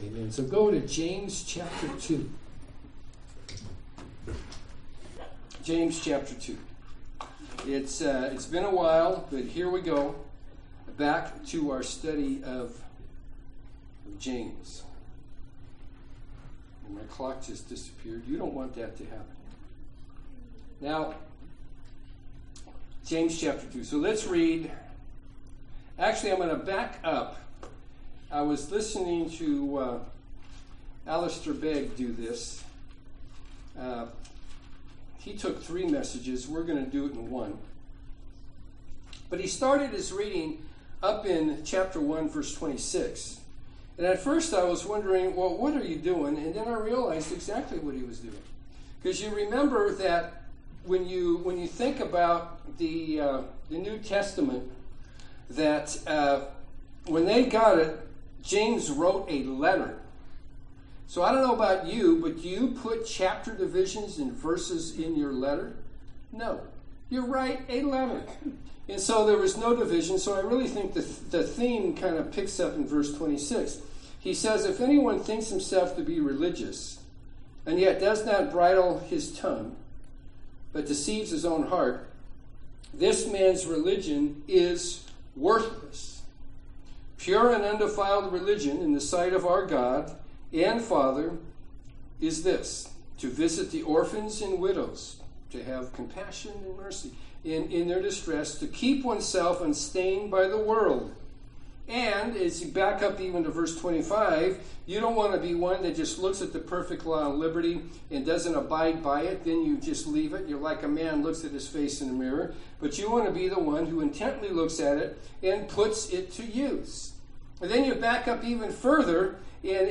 Sermons – Page 9 – Murphys Community Church